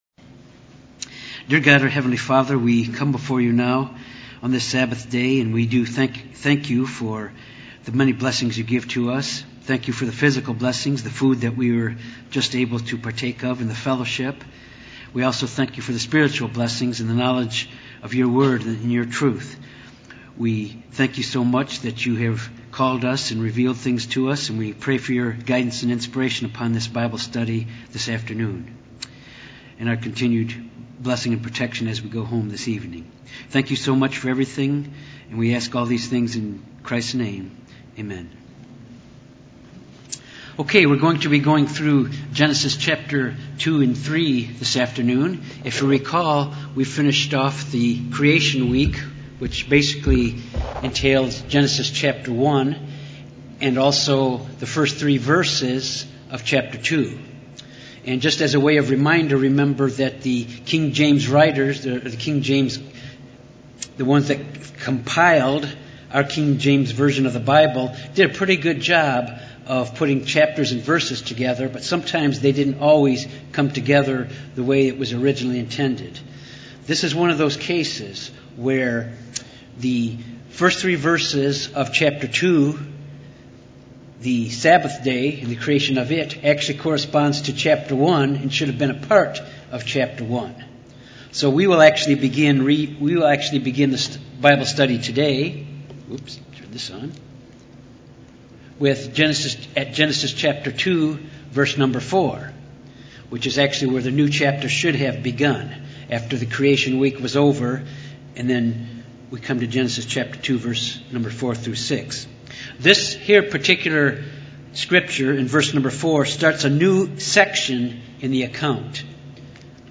This Bible Study focuses on Genesis 2-3. The creation of Adam and Eve.
Given in Little Rock, AR